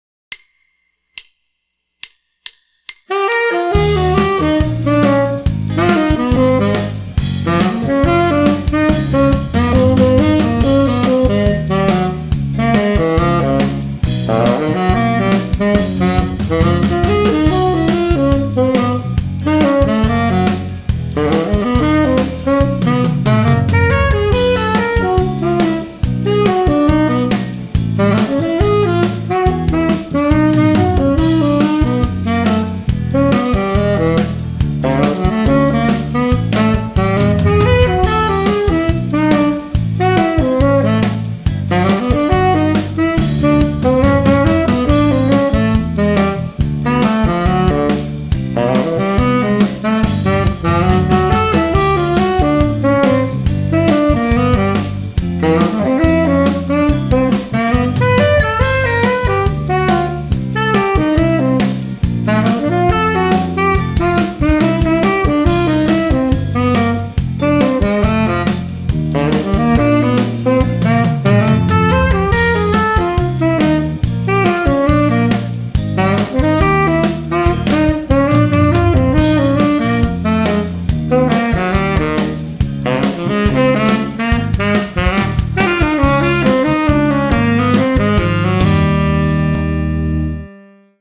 About eight days ago, I was “aimlessly blowing” the tenor sax.
By the time of this next variation, I had presented my idea to “the band,” (Band in a Box), but they were in a Dire Straits bag that day, so the chords got simplified, and the groove changed to straight eighths (dire str8s?).
Here is the final form of the phrase.
Below are the recording and chart for the lick in all 12 keys.